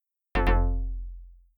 Incorrect Chime
alert bell chime correct ding ping ring tone sound effect free sound royalty free Sound Effects